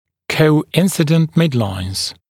[kəu’ɪnsɪdənt ‘mɪdlaɪnz][коу’инсидэнт ‘мидлайнз]совпадающие средние линии